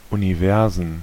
Ääntäminen
Ääntäminen Tuntematon aksentti: IPA: /uniˈvɛʁzn/ Haettu sana löytyi näillä lähdekielillä: saksa Käännöksiä ei löytynyt valitulle kohdekielelle. Universen on sanan Universum monikko.